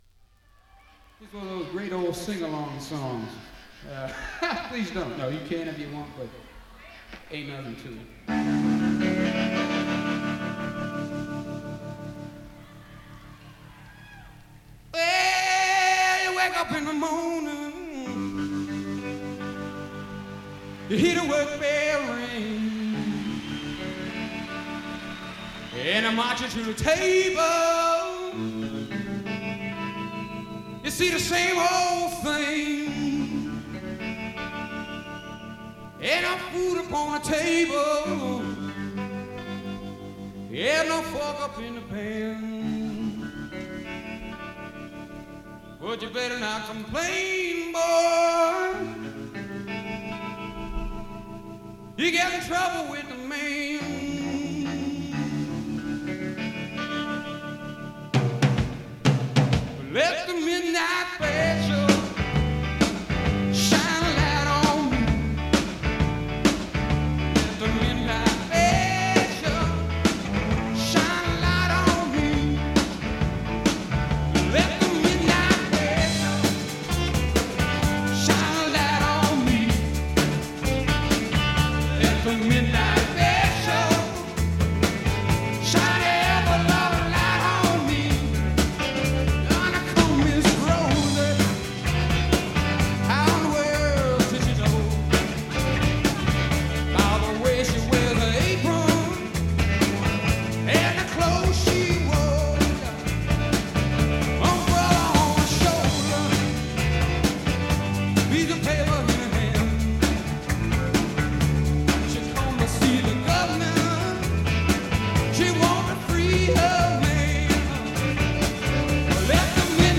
Recorded at London's Royal Albert Hall in April 1970.